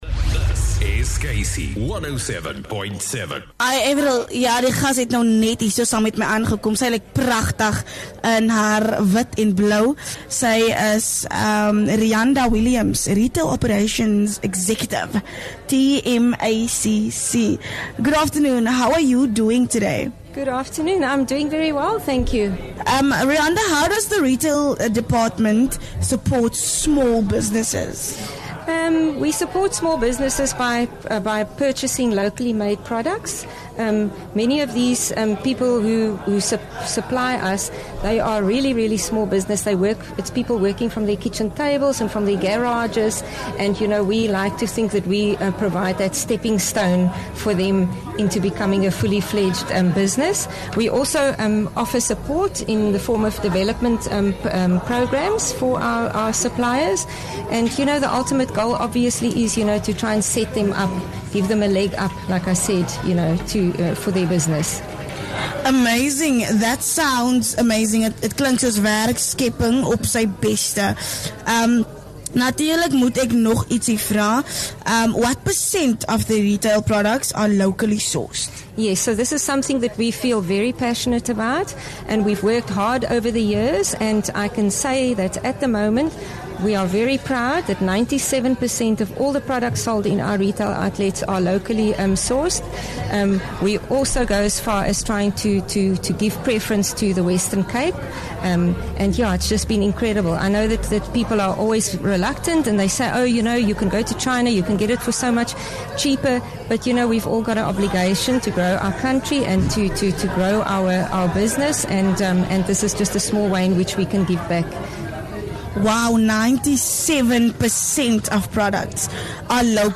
9 Oct KC107.7 LIVE from Table Mountain- 4 Oct 24